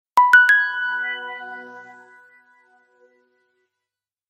Категория: SMS рингтоны | Теги: SMS рингтоны, Galaxy